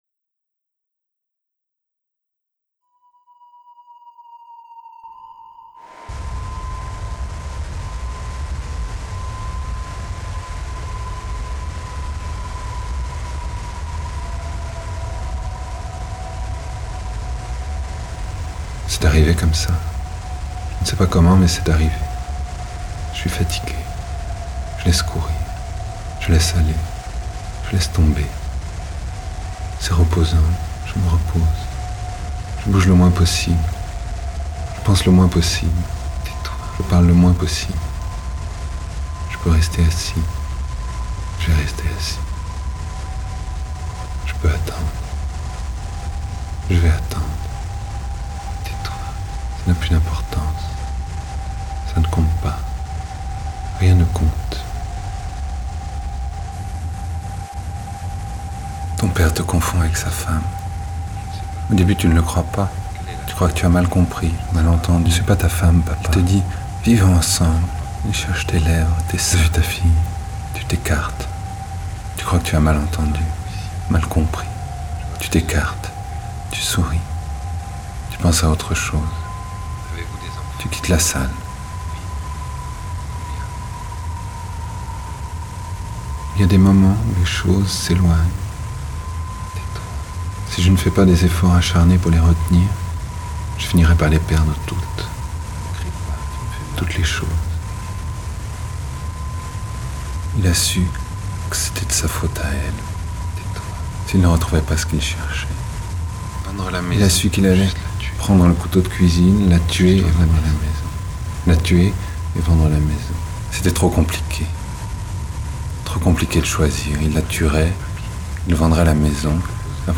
voix, bruitage